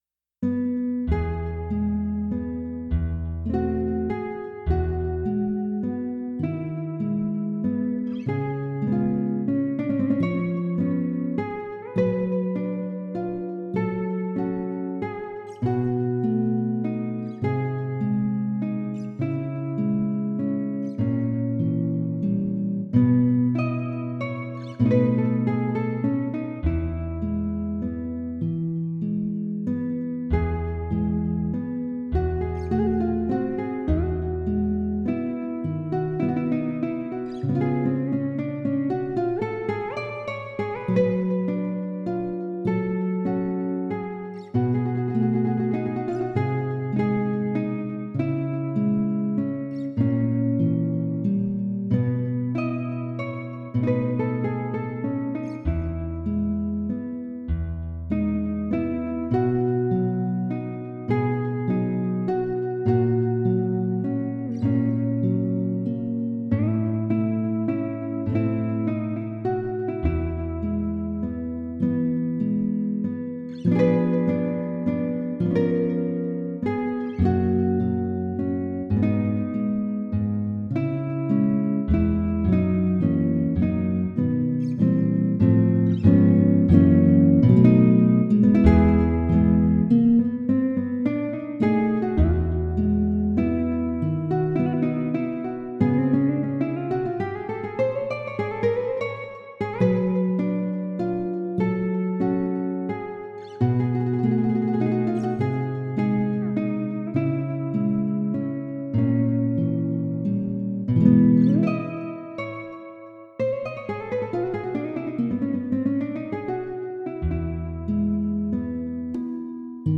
�l�h�c�h�@�N���V�b�N�M�^�[�@��z�ȁ@��i9-2�iNocturne OP.9-2�j